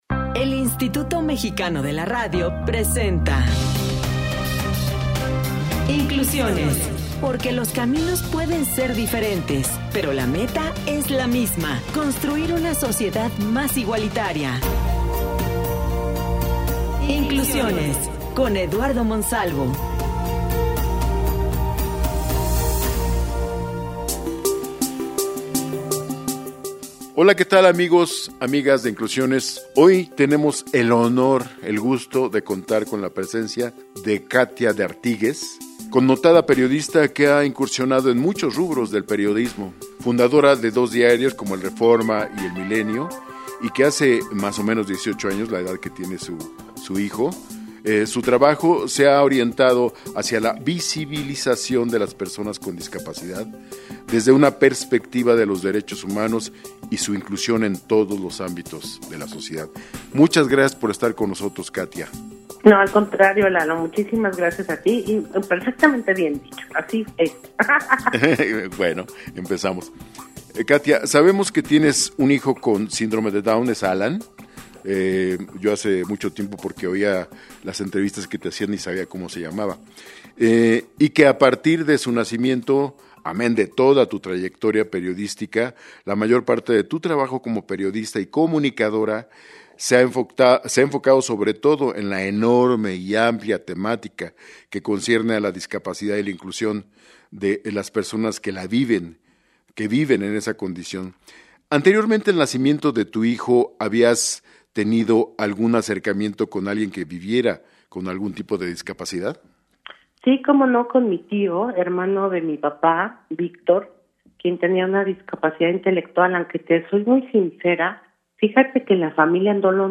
Platicamos con Katia D’Artigues, periodista y activista a favor de las personas con discapacidad, quien nos compartió su experiencia y cambio de enfoque a partir de convertirse en una madre de una persona con discapacidad.